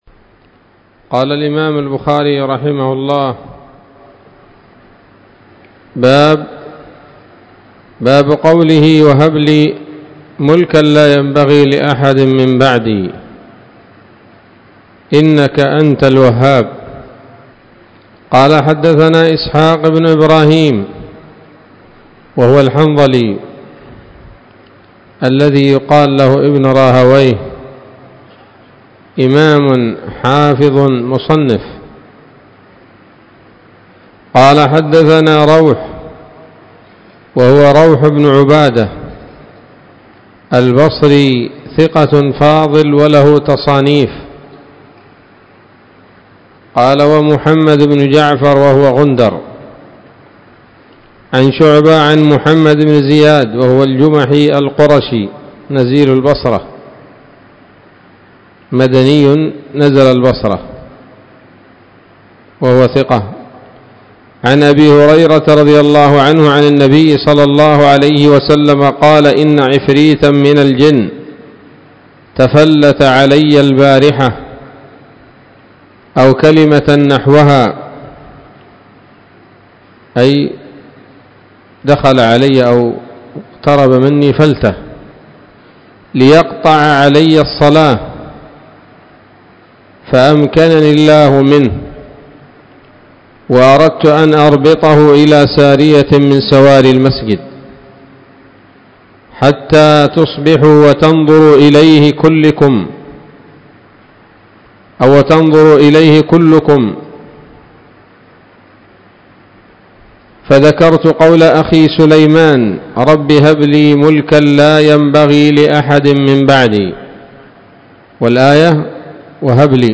الدرس الخامس عشر بعد المائتين من كتاب التفسير من صحيح الإمام البخاري